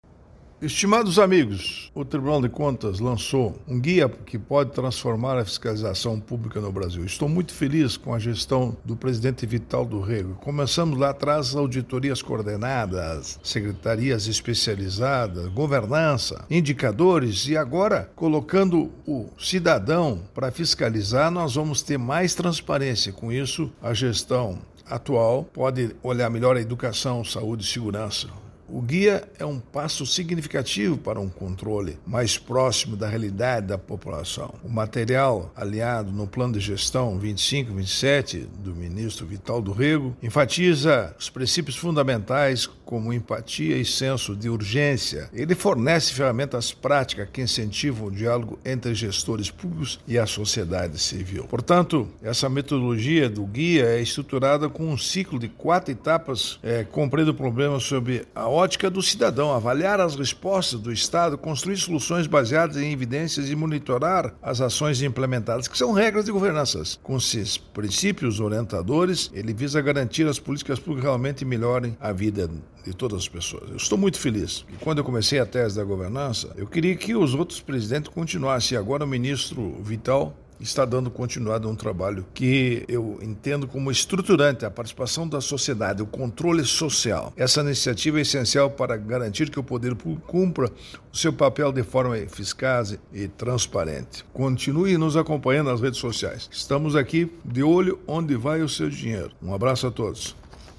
Comentário de Augusto Nardes.